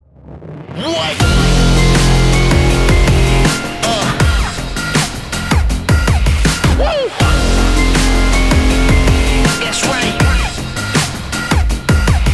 • Качество: 321, Stereo
мужской голос
громкие
Dubstep
Gangsta
тяжелые
Стиль: dubstep